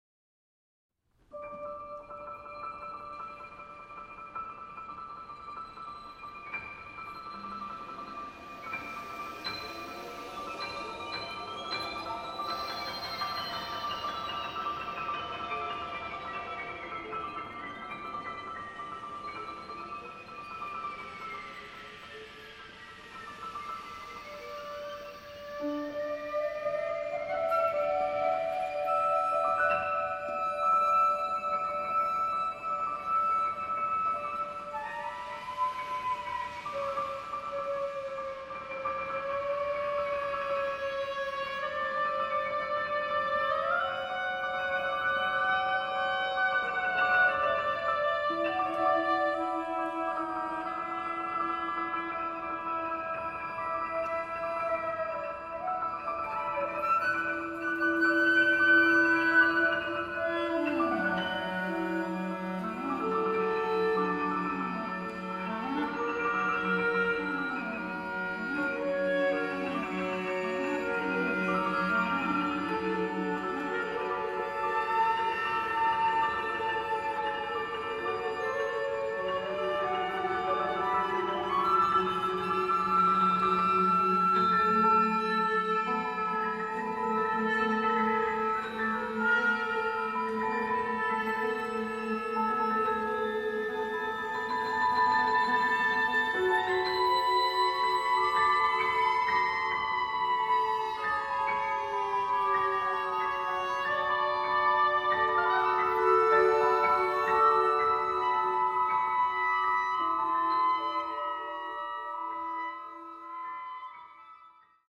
flute
oboe
clarinet
trumpet
violin
cello
guitar
percussion
piano